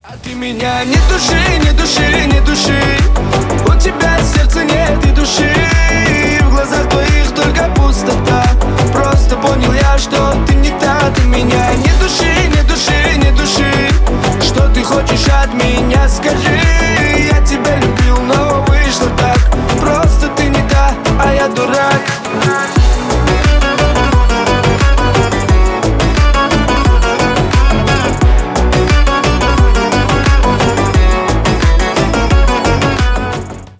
кавказские
поп